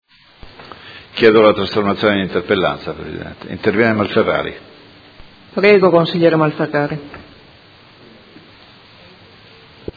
Seduta del 17/05/2018. Chiede trasformazione in interpellanza su interrogazione del Consigliere De Lillo (PD) avente per oggetto: Quartiere Madonnina - Verifica e aggiornamento del percorso contenuto nel Protocollo d’intesa, alla luce della nuova presenza di cattivi odori nell’area prospiciente le Fonderie Cooperative di Modena